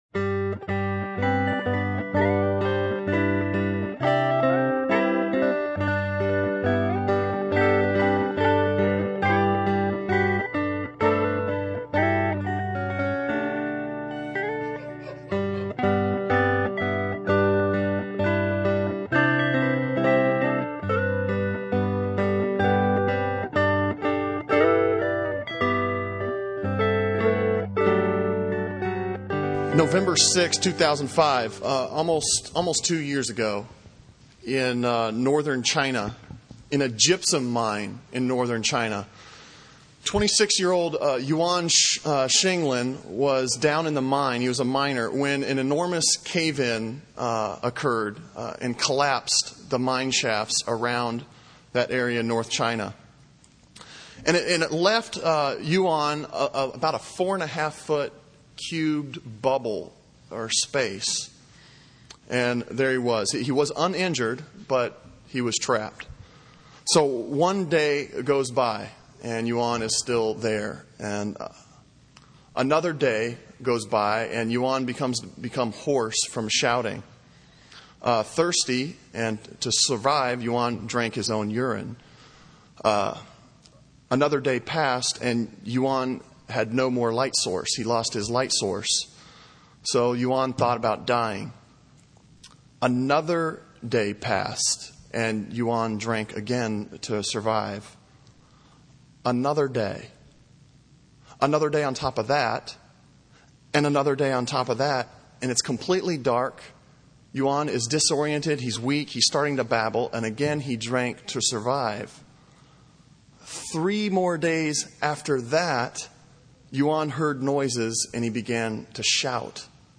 Sermon on I Thessalonians 3:6-13 from July 8